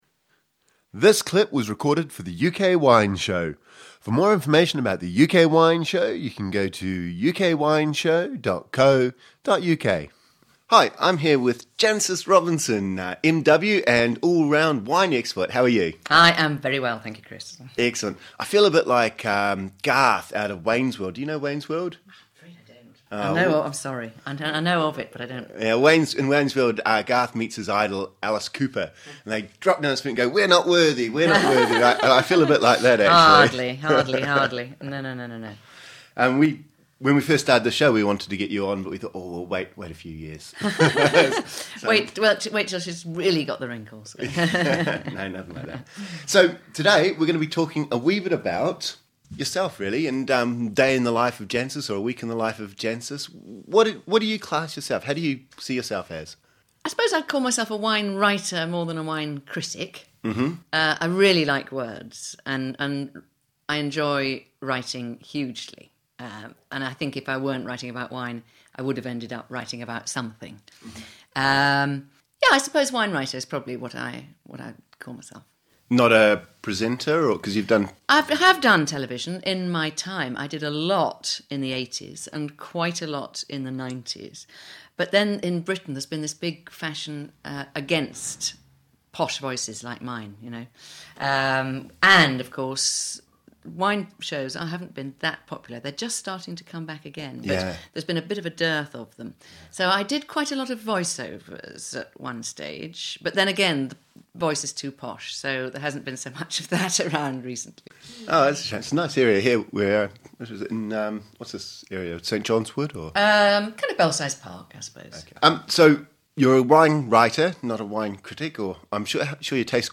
Jancis Robinson is a world-leading wine writer with a weekly column in the FT. We caught up with her and in the first of 3 interviews find out about a typical day in her life.